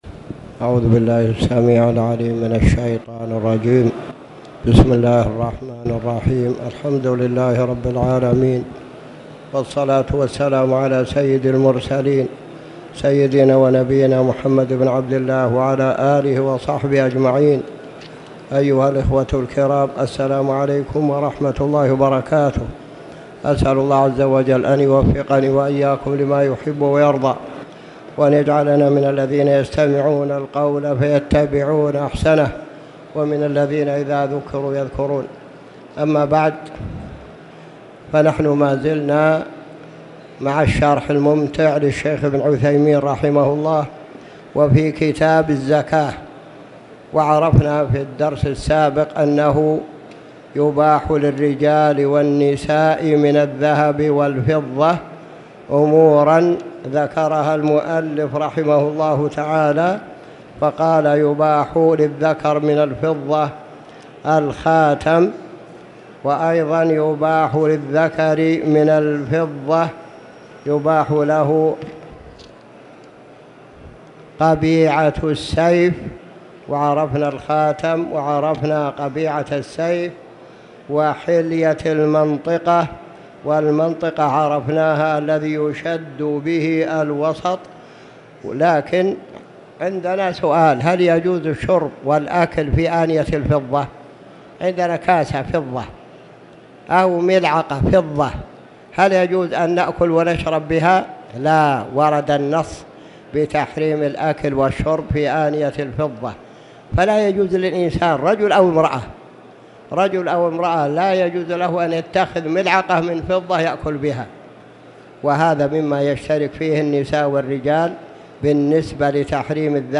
تاريخ النشر ٢١ جمادى الآخرة ١٤٣٨ هـ المكان: المسجد الحرام الشيخ